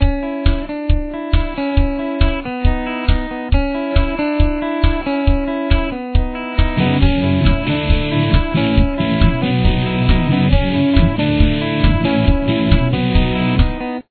Intro/Chorus